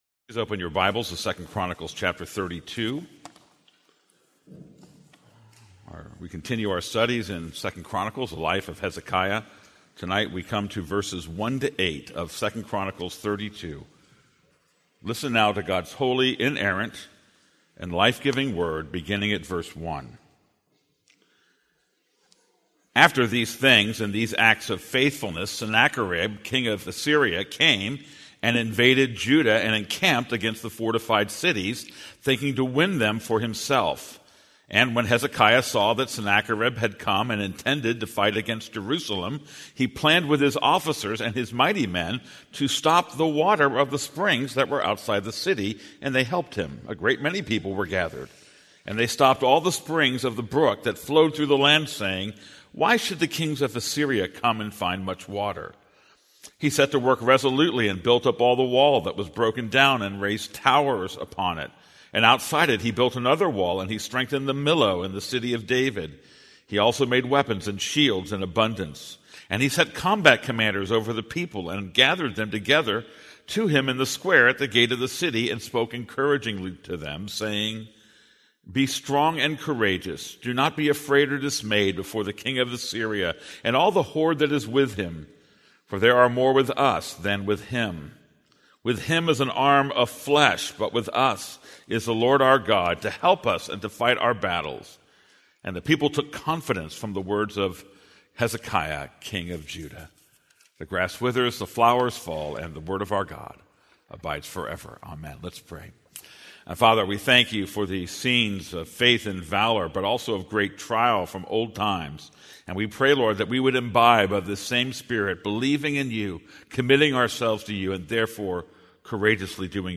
This is a sermon on 2 Chronicles 32:1-8.